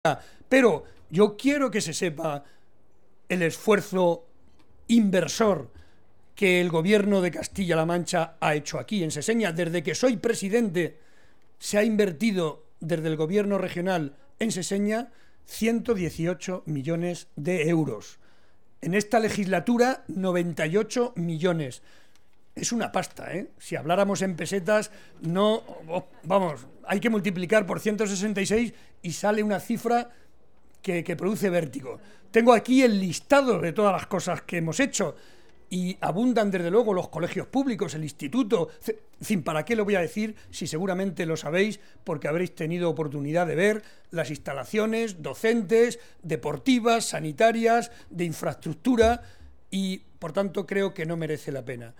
Barreda hacía estas declaraciones en el marco de un almuerzo con casi 200 personas procedentes de la zona de La Sagra toledana a los que ha asegurado que sus prioridades en la próxima Legislatura van a seguir siendo las políticas sociales: educación, bienestar social y sanidad.